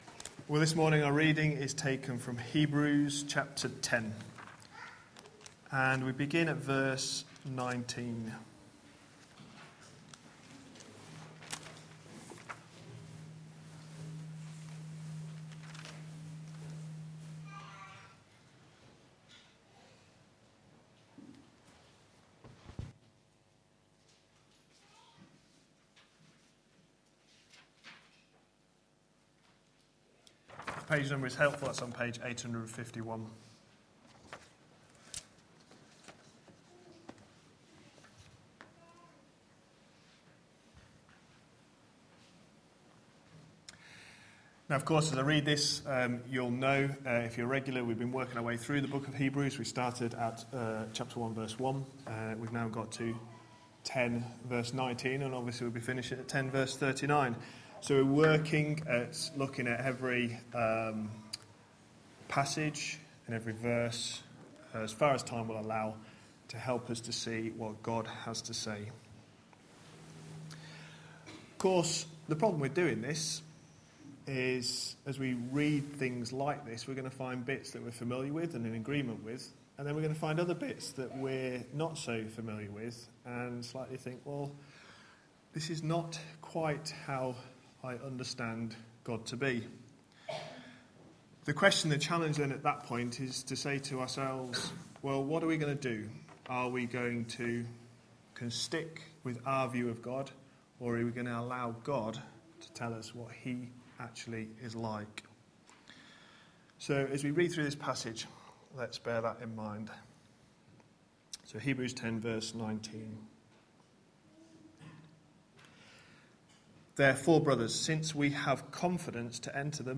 A sermon preached on 22nd February, 2015, as part of our Hebrews series.